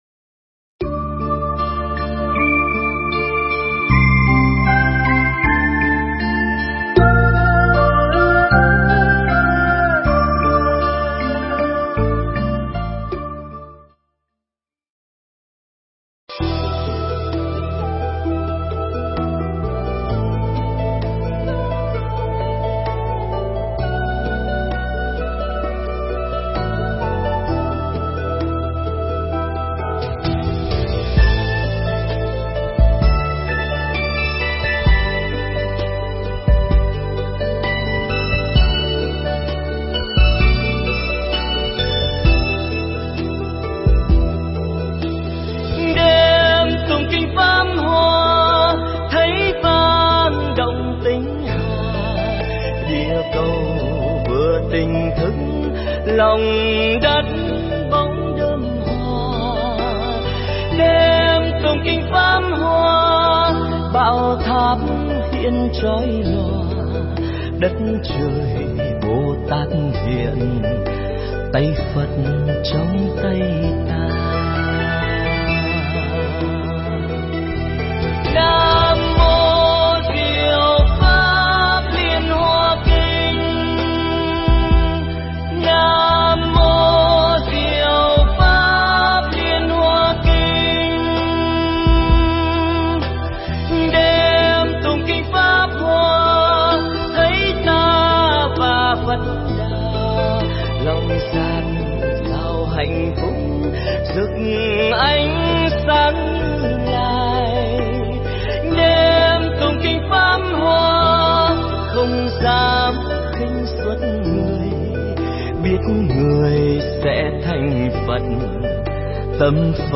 Mp3 Thuyết pháp Triết Lý Kinh Pháp Hoa